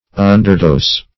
Meaning of underdose. underdose synonyms, pronunciation, spelling and more from Free Dictionary.
Search Result for " underdose" : The Collaborative International Dictionary of English v.0.48: Underdose \Un"der*dose`\, n. A dose which is less than required; a small or insufficient dose.